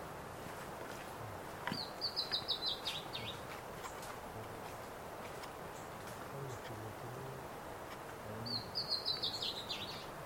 Bertoni´s Antbird (Drymophila rubricollis)
Sex: Male
Life Stage: Adult
Location or protected area: Parque Provincial Esmeralda
Condition: Wild